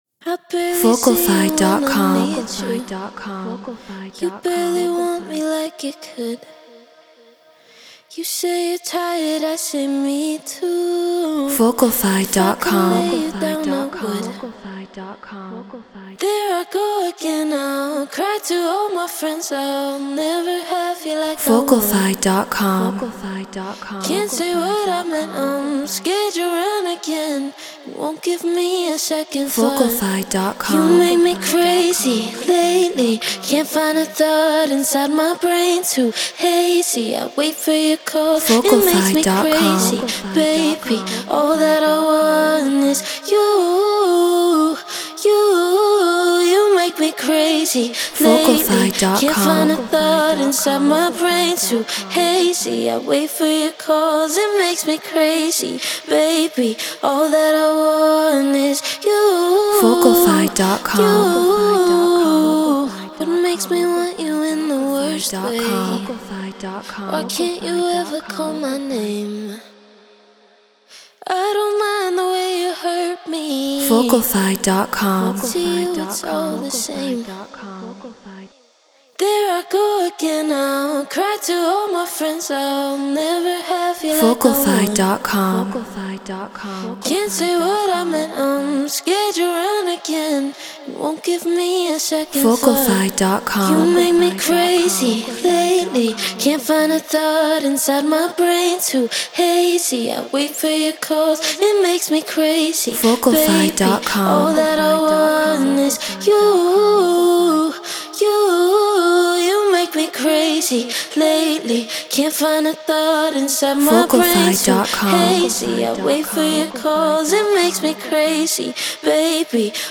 Get Royalty Free Vocals.